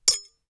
Metalic 3.wav